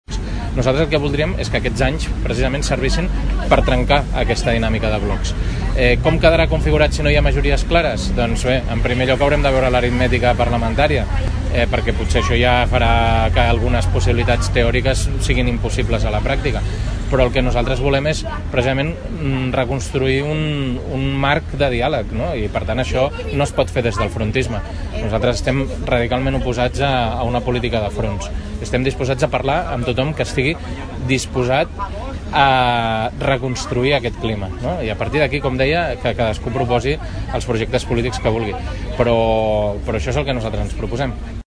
En el marc del mercat dels diumenges, el dirigent socialista va aprofitar per parlar amb el visitants amb la voluntat d’explicar els objectius del PSC per aquestes eleccions.